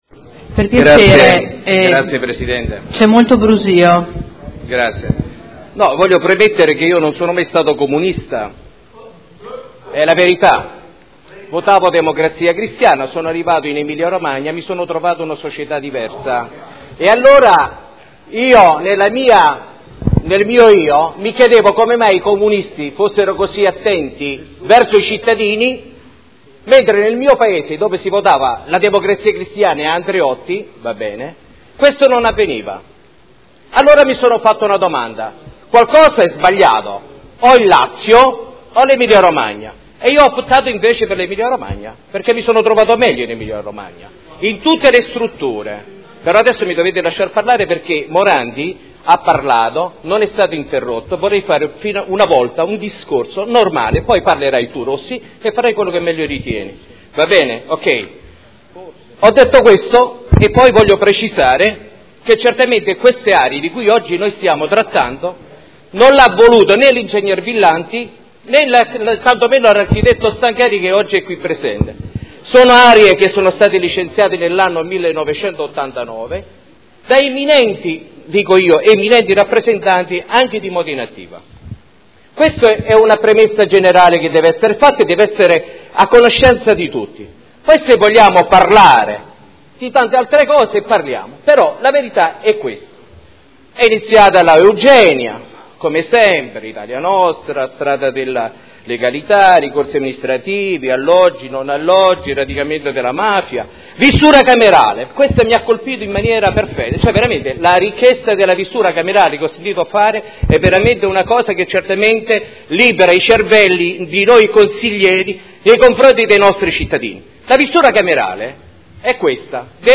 Maurizio Dori — Sito Audio Consiglio Comunale